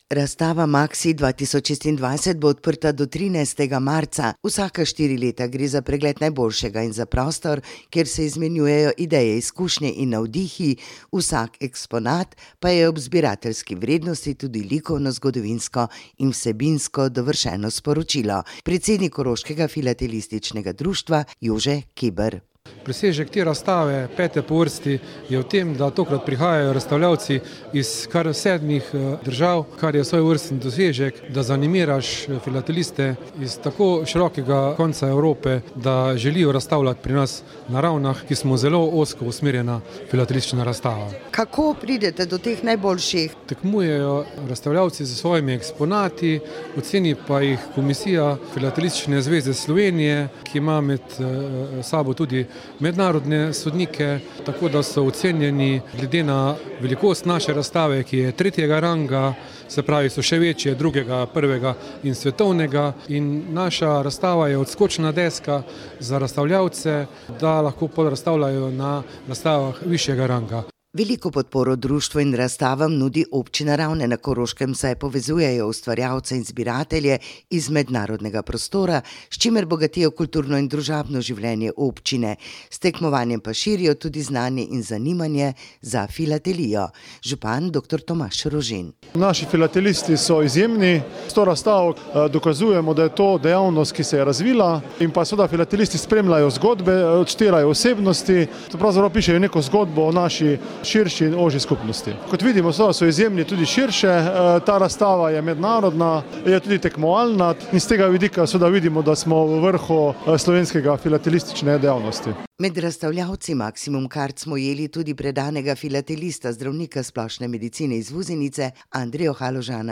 zvočni posnetek poročil 5.3.2026 —- klikni —